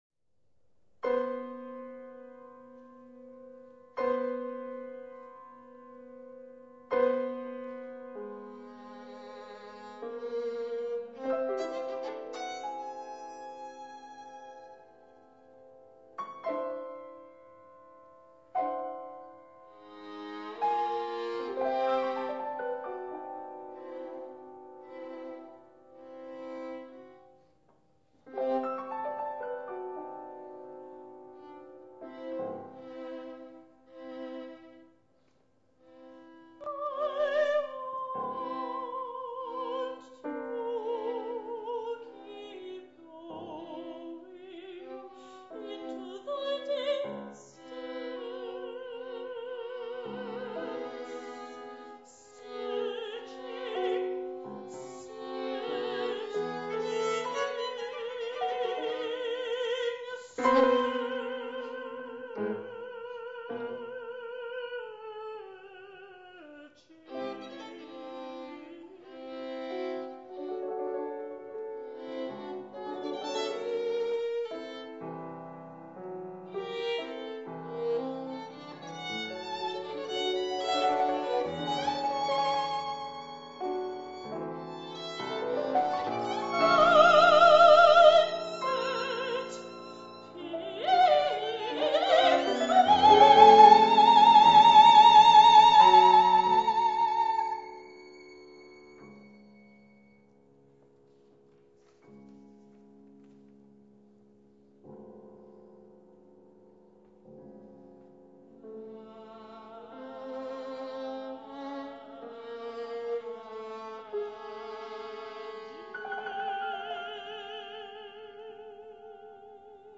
World Premiere-24 5 15 (National Portrait Gallery)